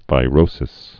(vī-rōsĭs)